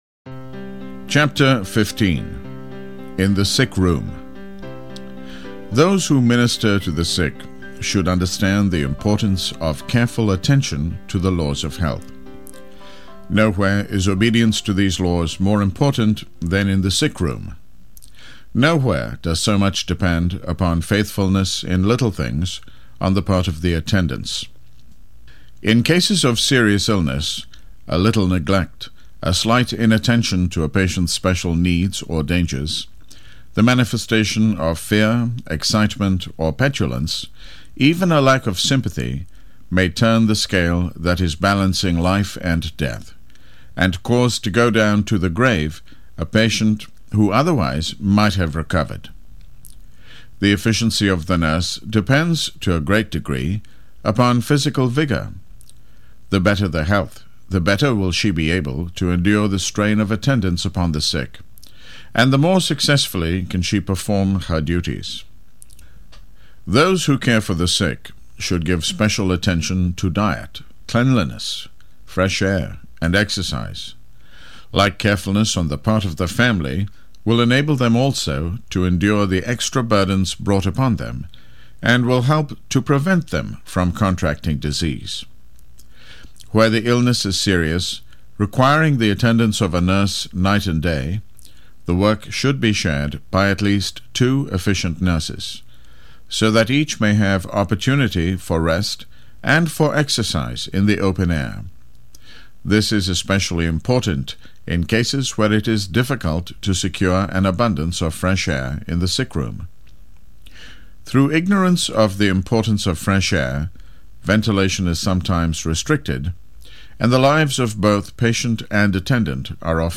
The Ministry of Healing MP3 Audio Book - 588MB sample